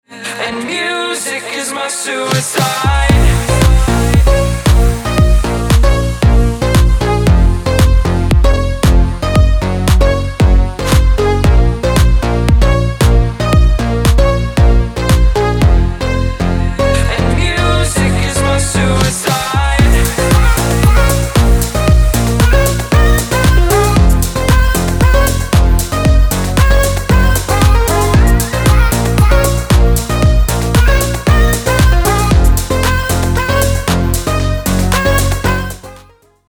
• Качество: 320, Stereo
громкие
deep house
атмосферные
EDM
club
забавный голос
house